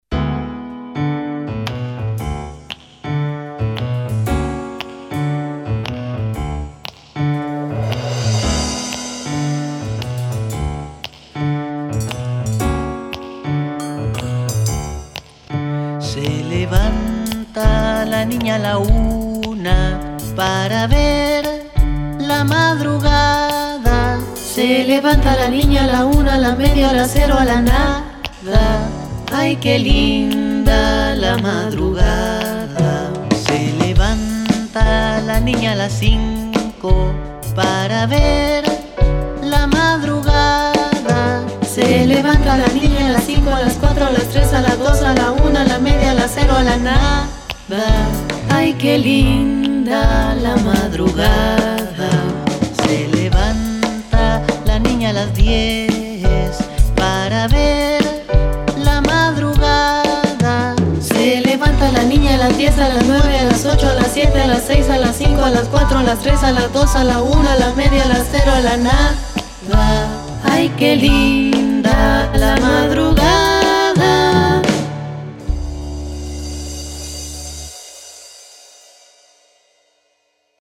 Tradicional puertorriqueña